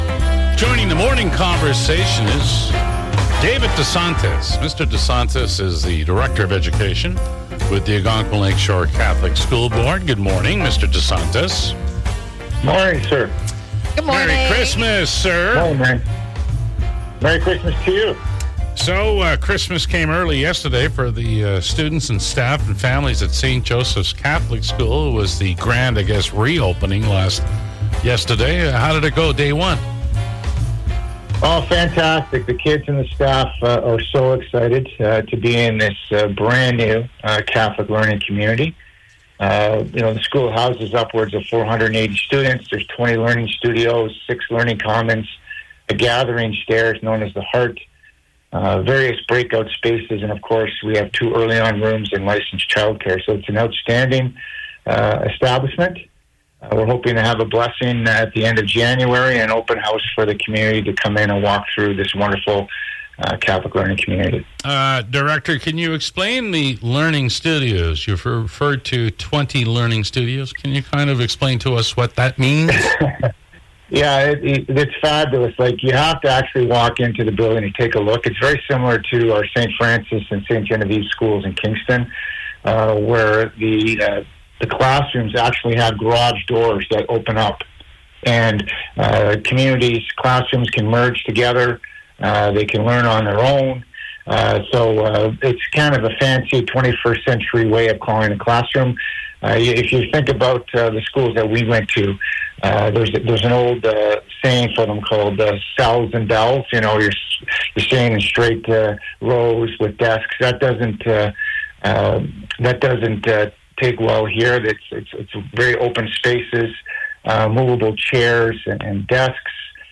St. Joseph Catholic School has reopened! Listen to the MIX Morning Crew in conversation